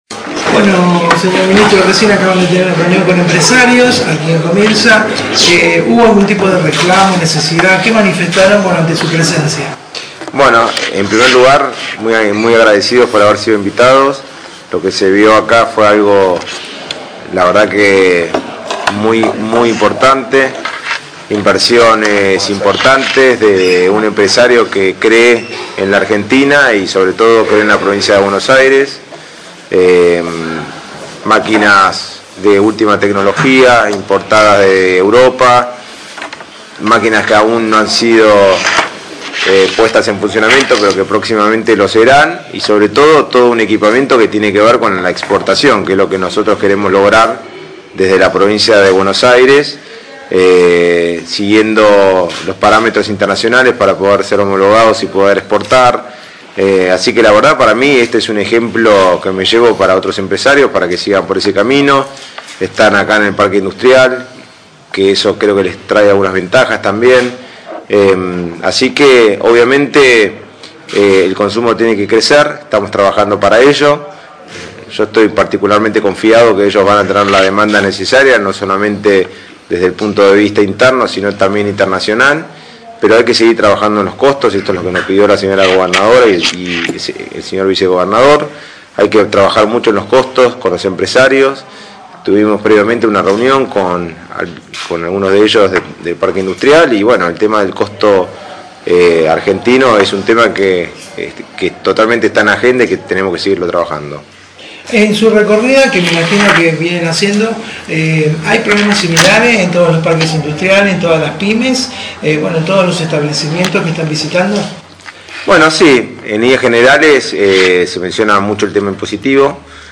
CONFERENCIA DE PRENSA EN EL FRIGORÍFICO MARK
Luego de la recorrida por la planta estaba organizada una conferencia de prensa para medios locales donde tanto el vice gobernador y el ministro de industria accedieron a todas las preguntas.